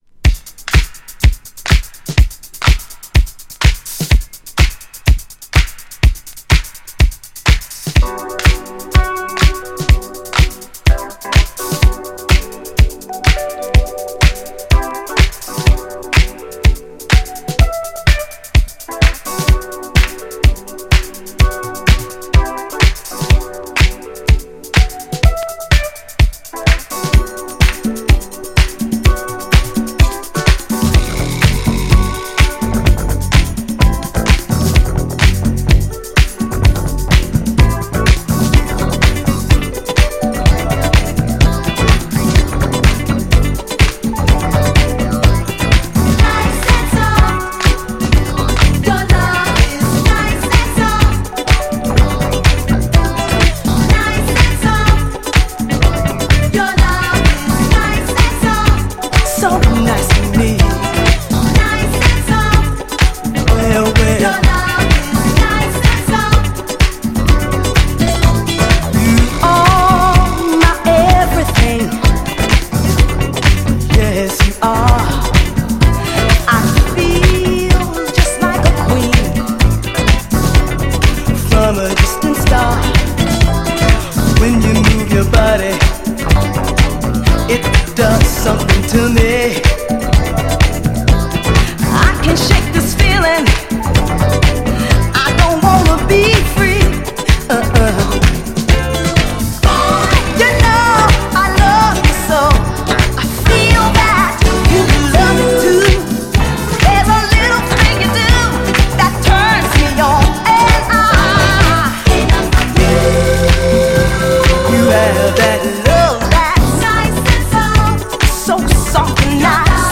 GENRE Dance Classic
BPM 126〜130BPM
アップリフティング # エモーショナル # コズミック # パーカッシブ